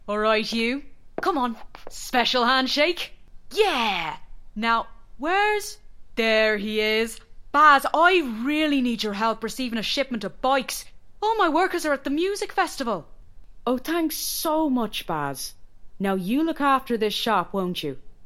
Their voice has a lovely clear, engaging and warm tone.
Irish